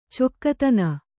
♪ cokkatana